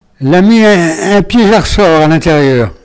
Sables-d'Olonne (Les)
locutions vernaculaires